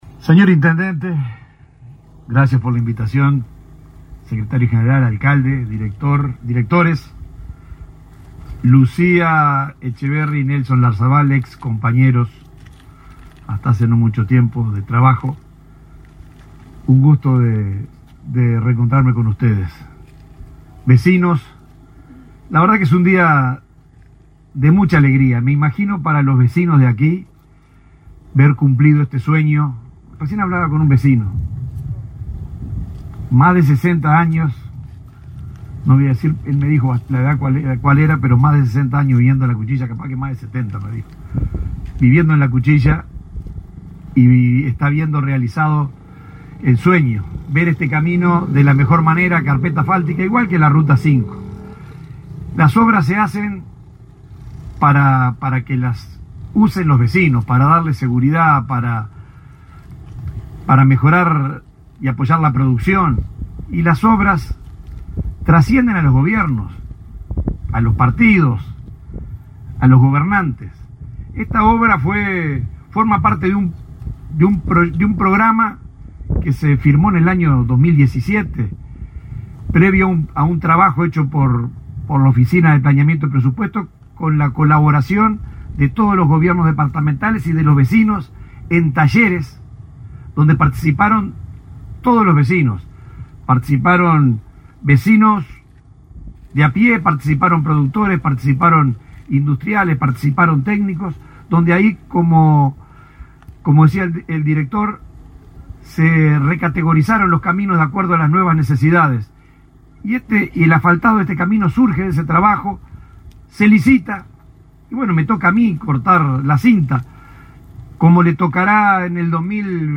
Palabras del subdirector de la OPP, Benjamín Irazábal
La Oficina de Planeamiento y Presupuesto (OPP) inauguró, este 16 de marzo, obras por 42 millones de pesos en el camino Al Gigante, en Canelones.